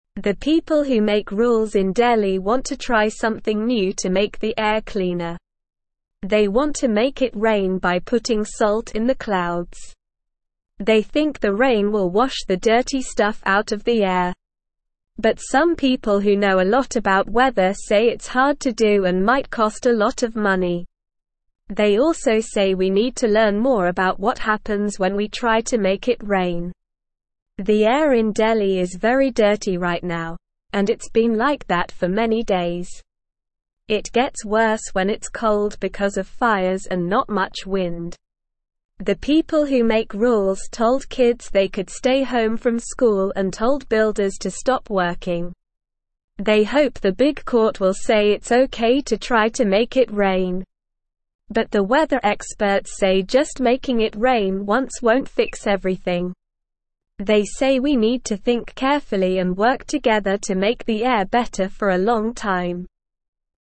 Slow
English-Newsroom-Beginner-SLOW-Reading-Making-Rain-to-Clean-Delhis-Dirty-Air.mp3